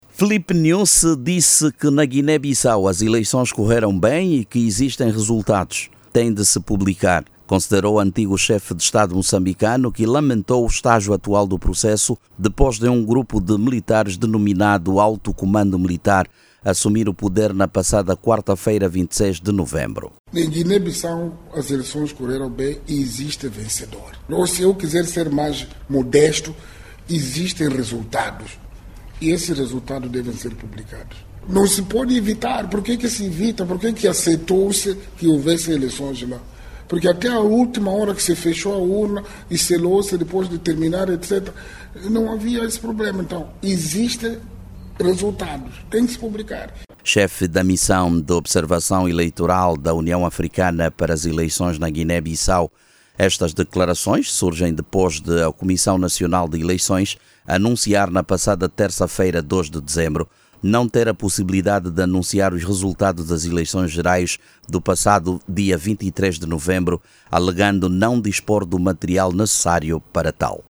Em declarações à Rádio Moçambique, o antigo Presidente da República afirmou que as eleições “correram bem” e que “existe um vencedor”.